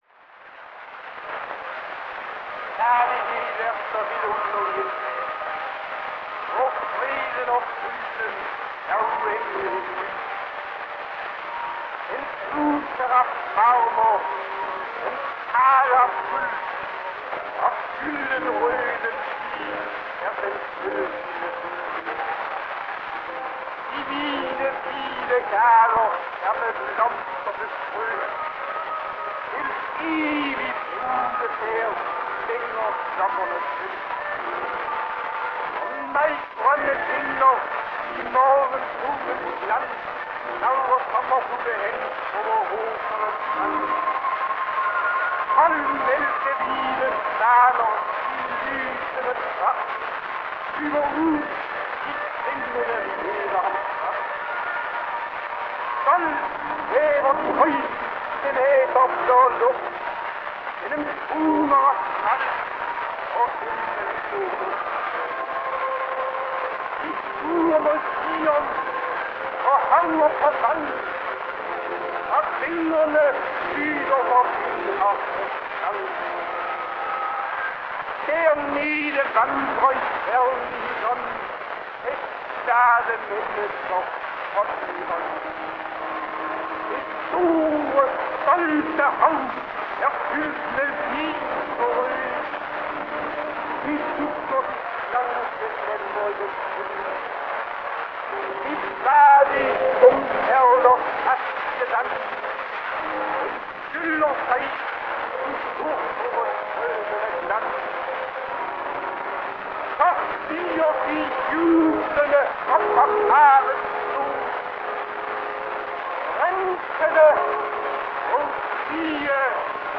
Musikledsaget monolog-scene fra Hauptmanns Lille Hanne.